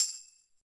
soft-slidertick.ogg